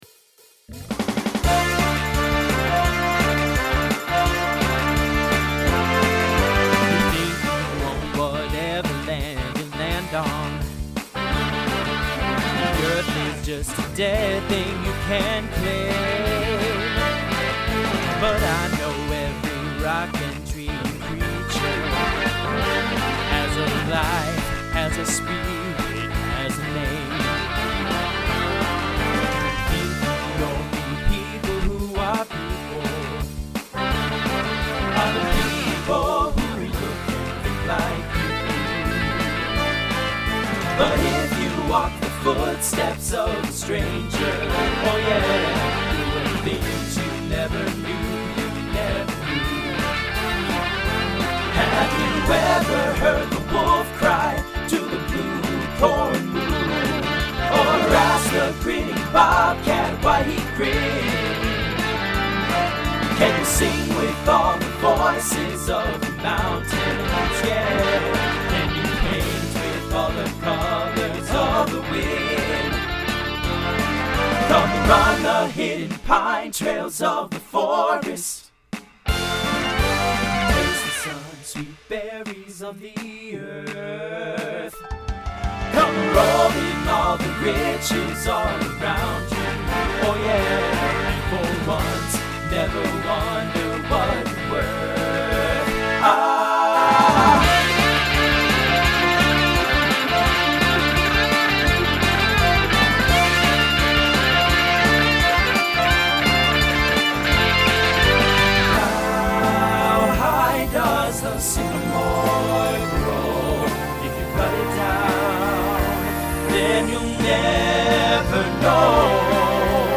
Voicing TTB Instrumental combo Genre Broadway/Film , Rock